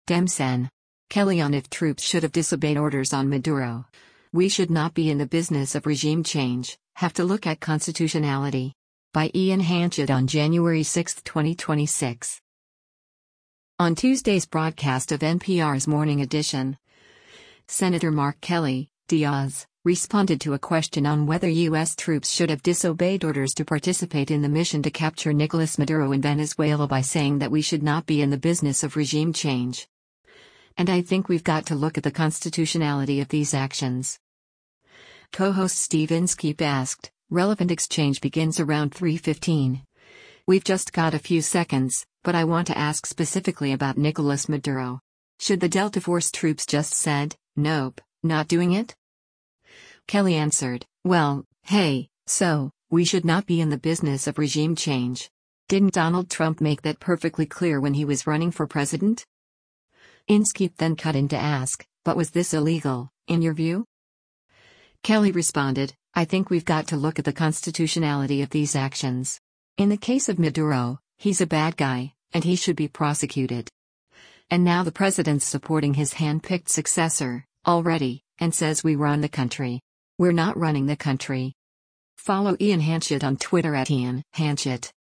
On Tuesday’s broadcast of NPR’s “Morning Edition,” Sen. Mark Kelly (D-AZ) responded to a question on whether U.S. troops should have disobeyed orders to participate in the mission to capture Nicolas Maduro in Venezuela by saying that “we should not be in the business of regime change.”